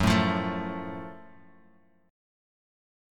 GbmM7 chord